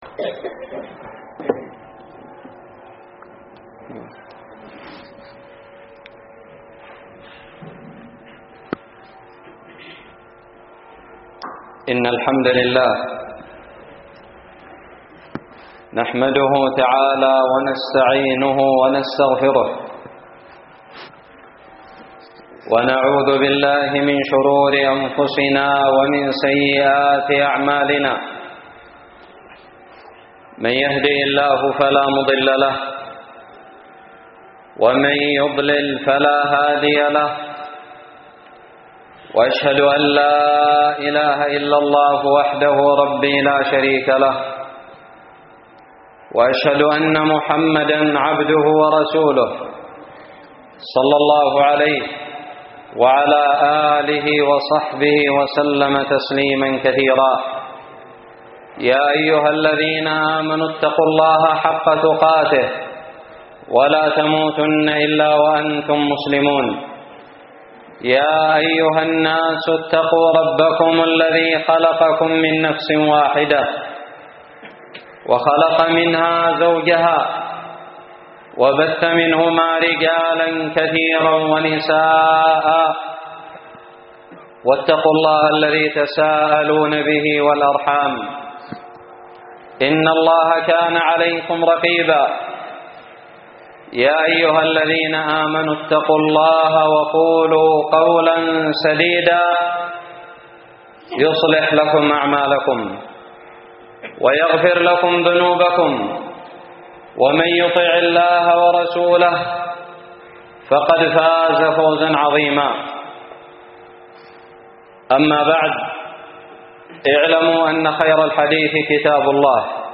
خطب الجمعة
ألقيت بدار الحديث السلفية للعلوم الشرعية بالضالع في 20 رجب 1439هــ